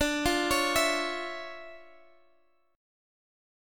DmM9 Chord
Listen to DmM9 strummed